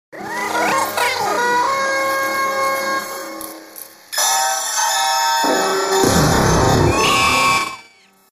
Horor ? sound effects free download